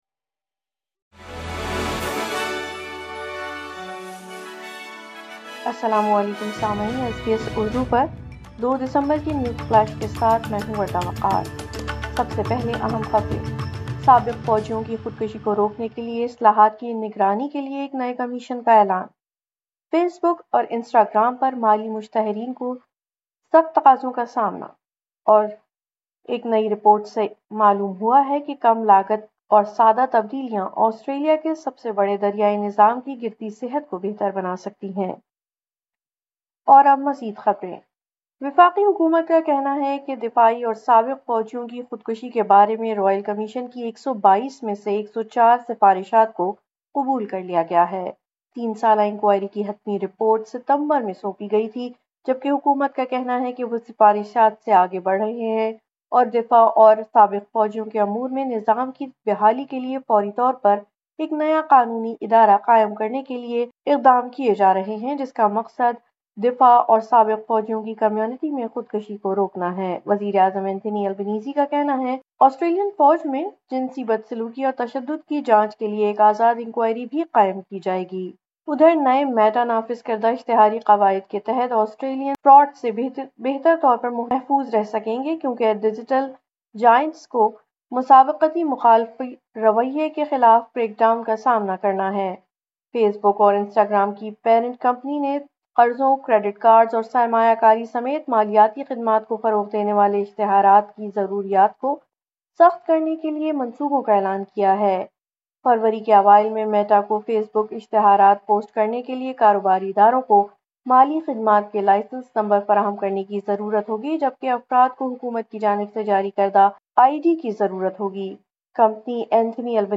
اردو نیوز فلیش:02 دسمبر 2024